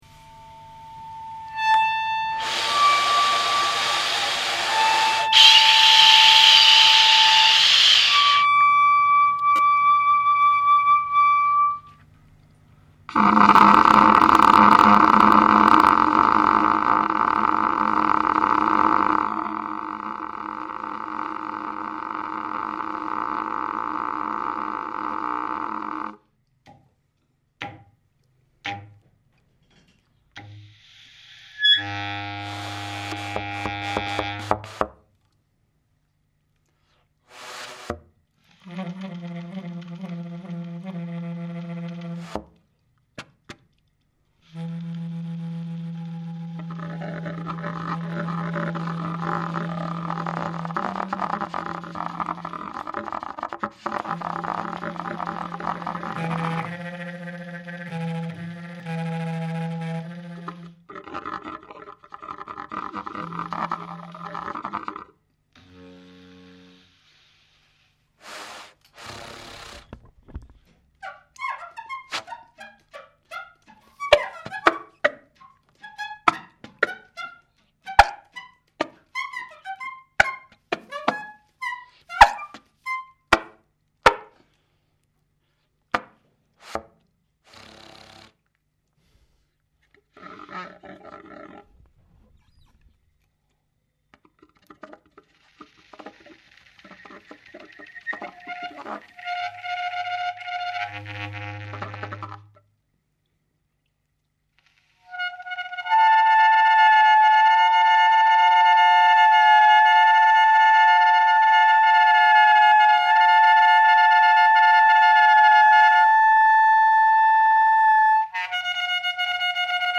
musik aus dem studio 1: